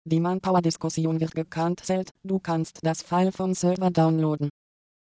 diphone-synthesis